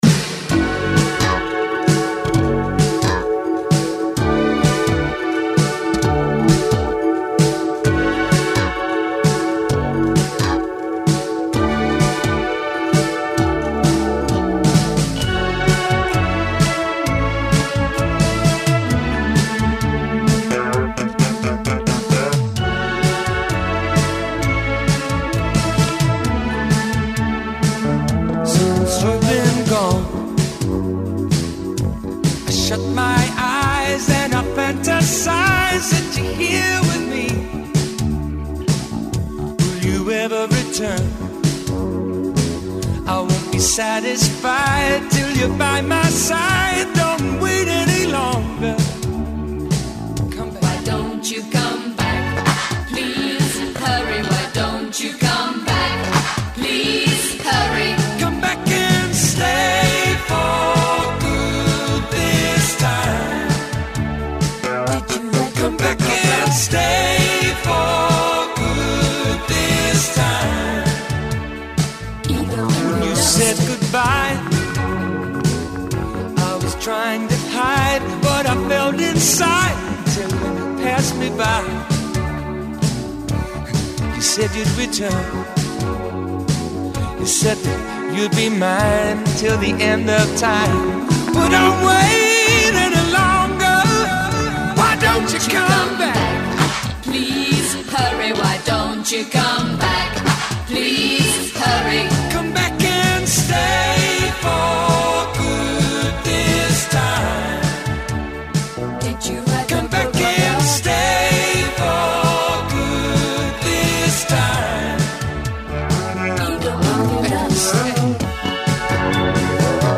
Tous les vendredis soirs de 20h à 21h Les meilleurs titres des années 70, 80 et 90